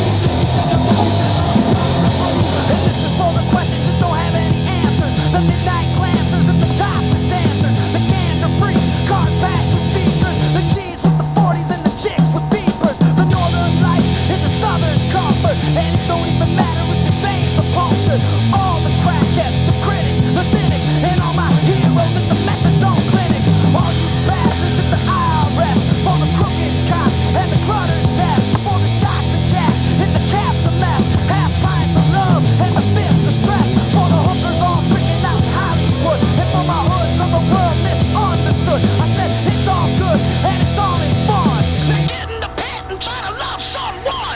Everything from rap to the blues.